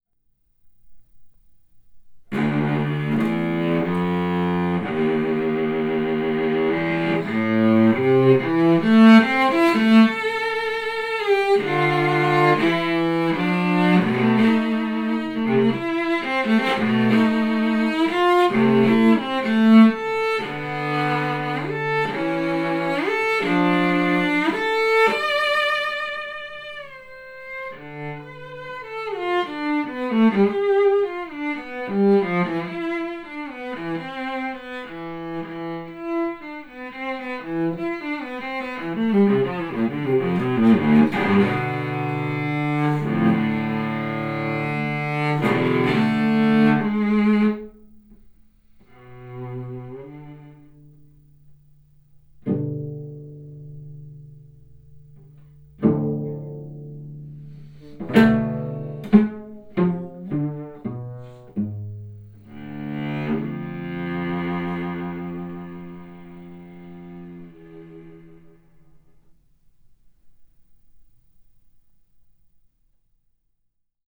Klangvergleich gespielt von Maximilian Hornung
Cello A: